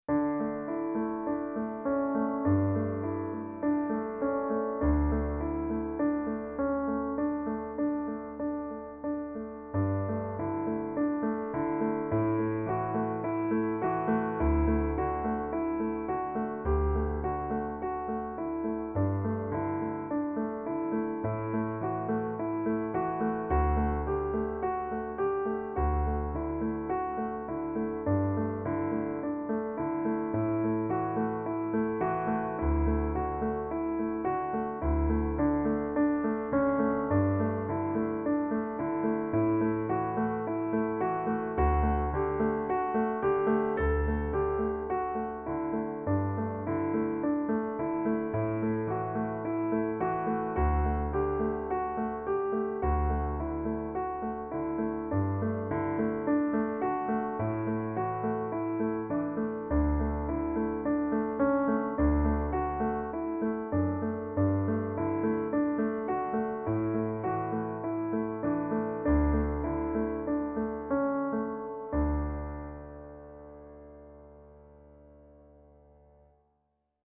This is the piano accompaniment for a liturgical song I call Mystery of Faith. The piano is about as simple as it can be (never are there more than 2 notes playing at the same time), but I think it stands reasonably well on its own.